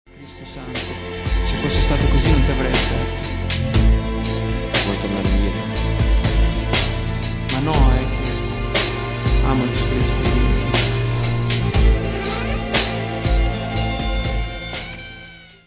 Gente al porto di Rimini
Download un'anteprima di bassa qualità